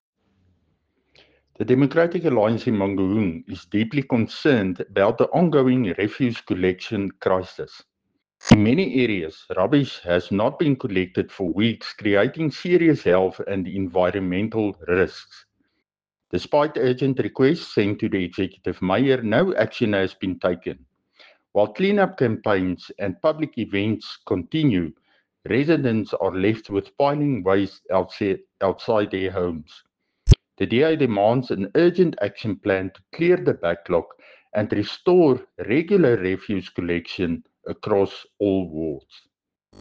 Afrikaans soundbites by Cllr Dirk Kotze and Sesotho soundbite by Jafta Mokoena MPL.